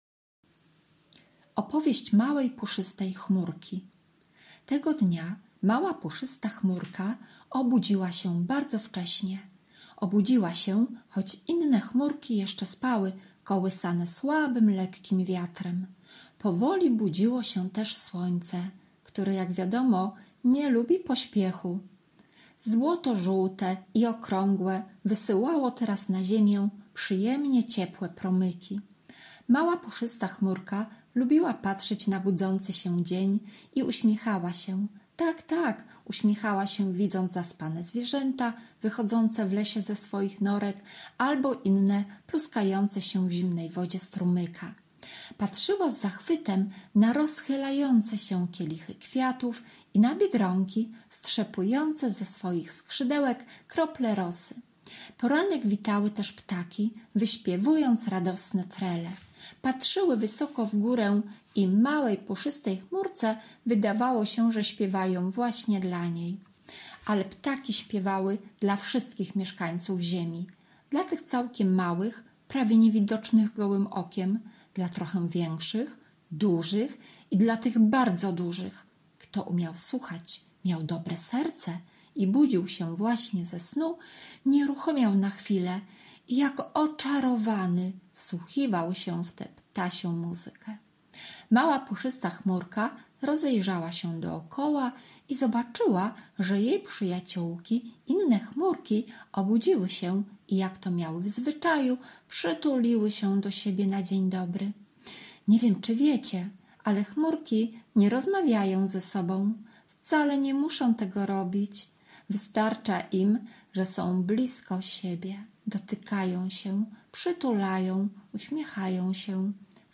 OPOWIEŚĆ MAŁEJ PUSZYSTEJ CHMURKI – opowiadanie B.Domańska mała, puszysta chmurka Tęsknimy za tym czasem, kiedy siadaliście koło nas, a my czytałyśmy Wam przeróżne historie, bajki, opowiadania i wierszyki…